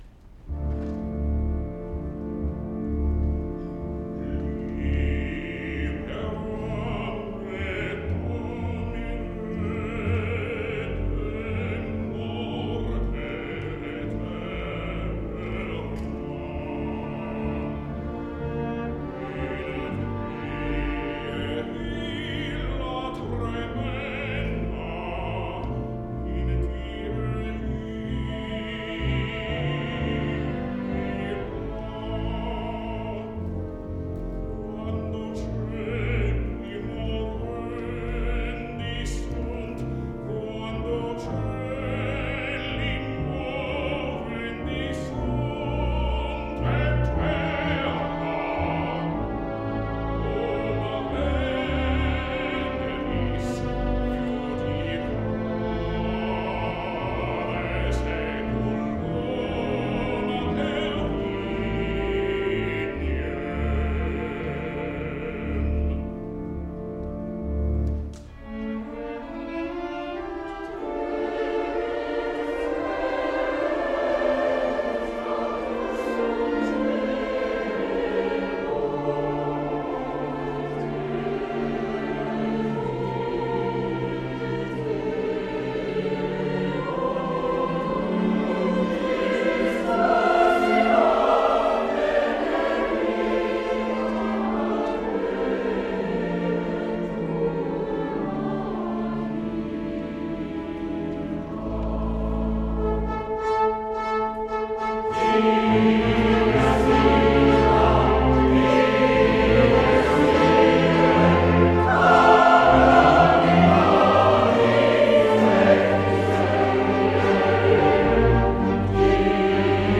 baritone